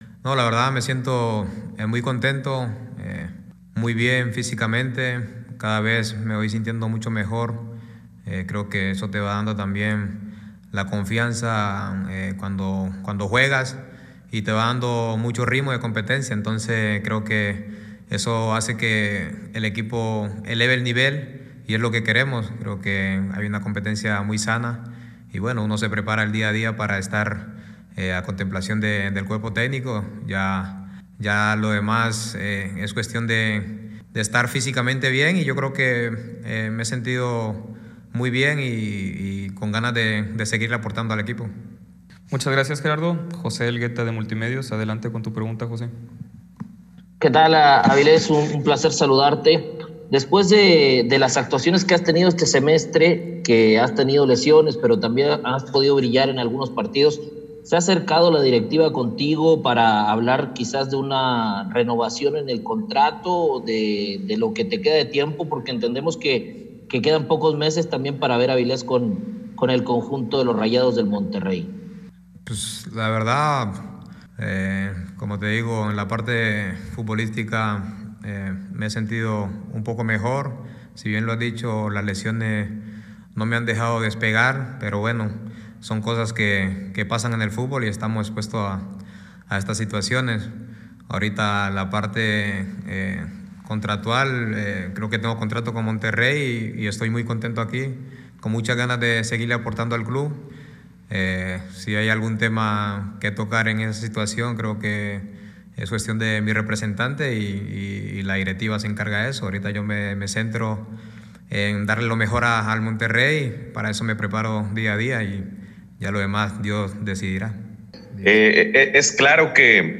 Siempre he estado comprometido con el club, me he entregado al máximo», expresó en rueda de prensa.